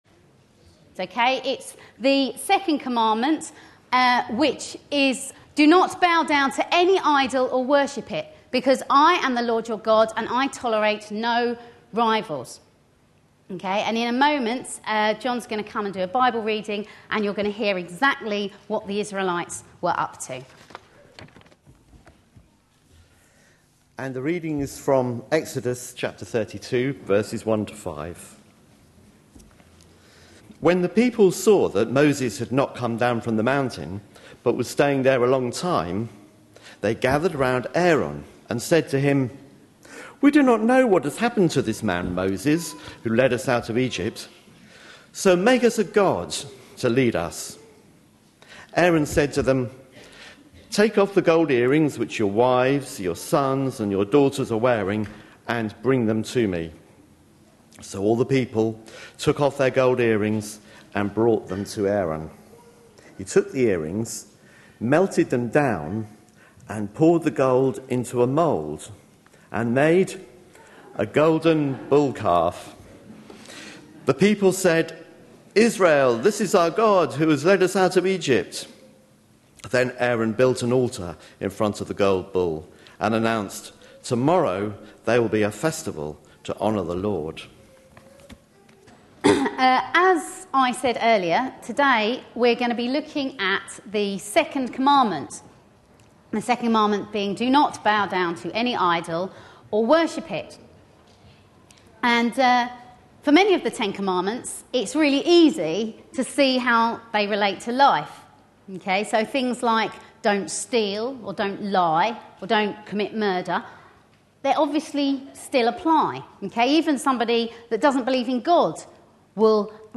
A sermon preached on 17th June, 2012, as part of our Family Service - The Ten Commandments series.
This was part of a monthly all-age service.